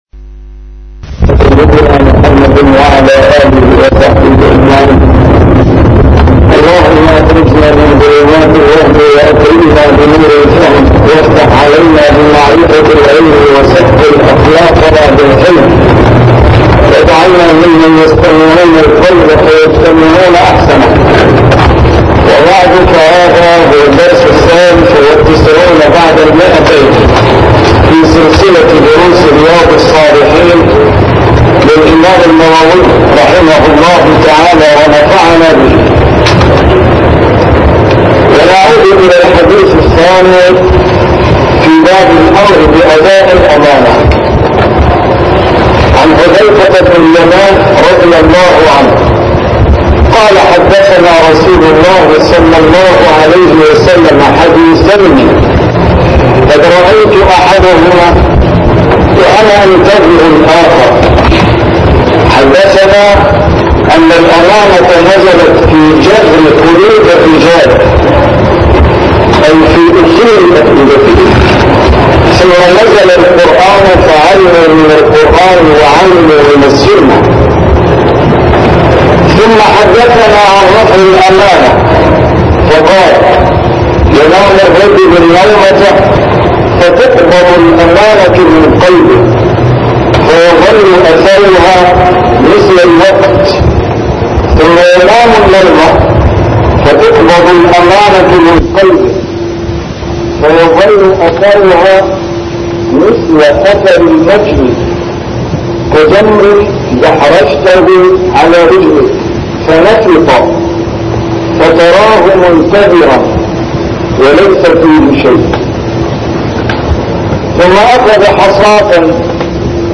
A MARTYR SCHOLAR: IMAM MUHAMMAD SAEED RAMADAN AL-BOUTI - الدروس العلمية - شرح كتاب رياض الصالحين - 293- شرح رياض الصالحين: الأمر بأداء الأمانة